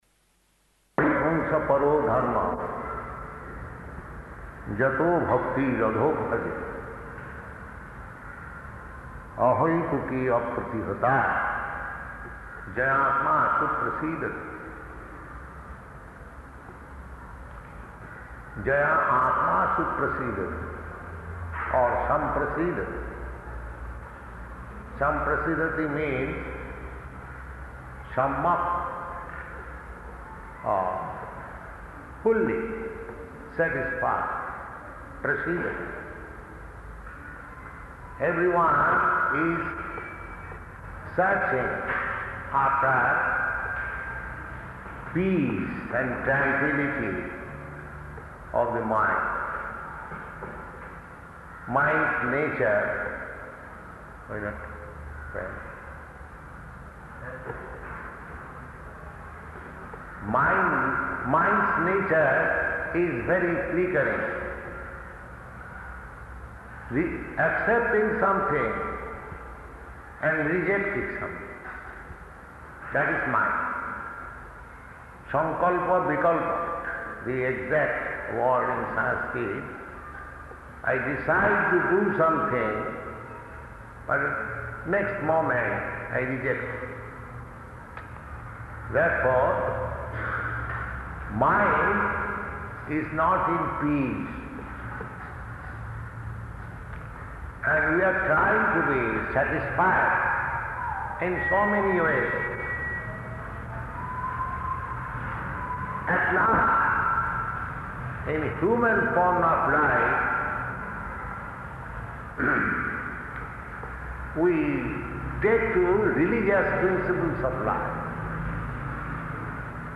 Location: Mombasa